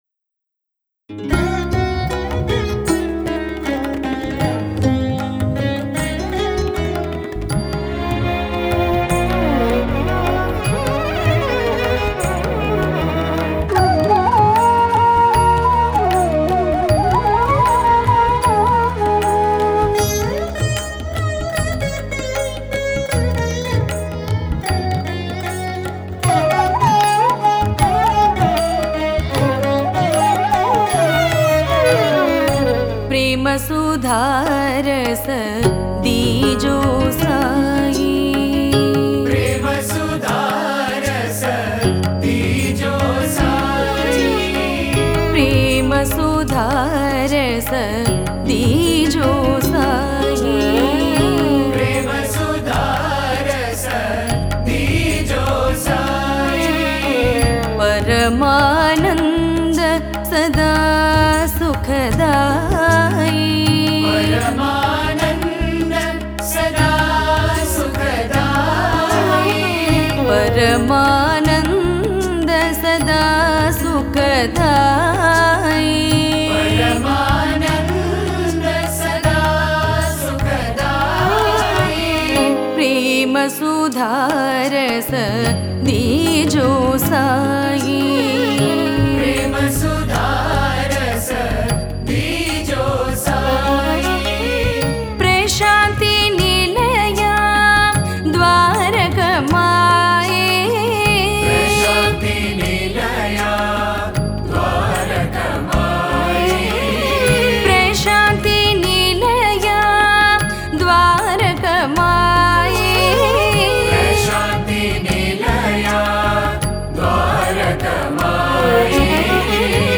Author adminPosted on Categories Sai Bhajans